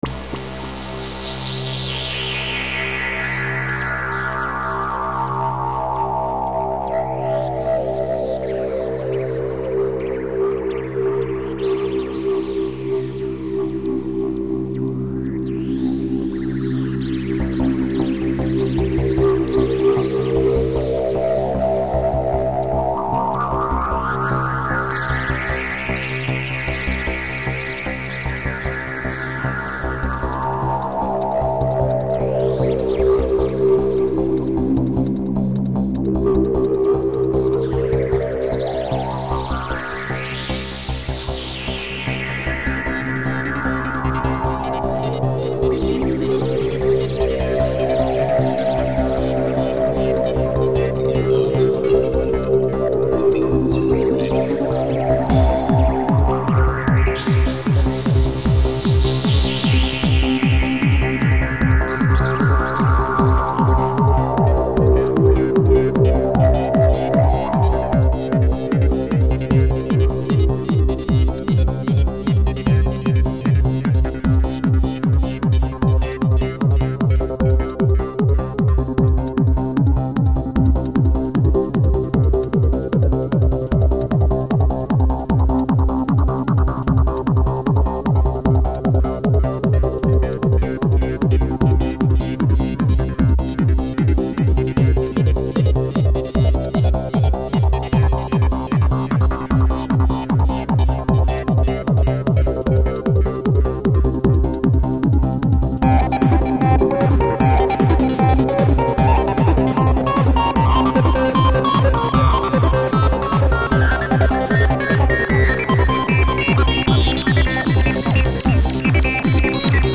Style: Goa Trance